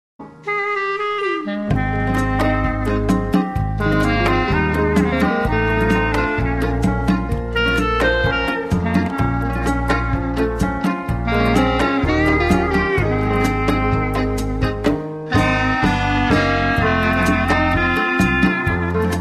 Garage Synth